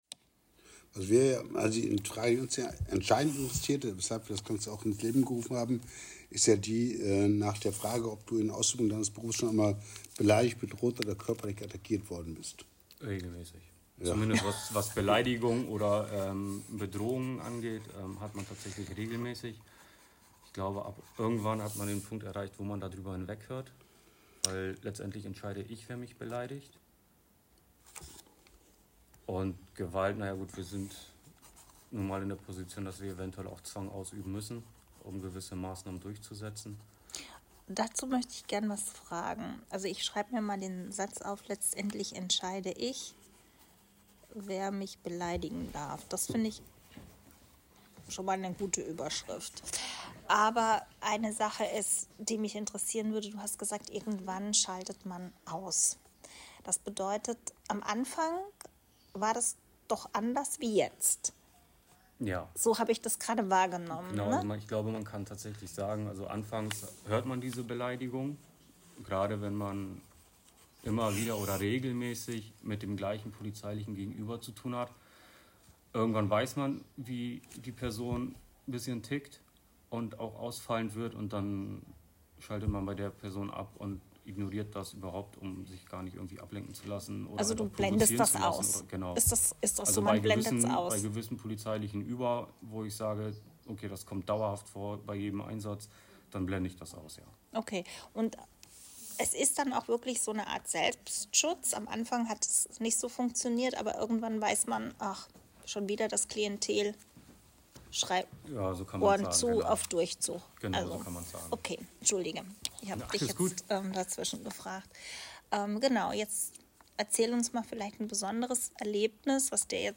Audio Interview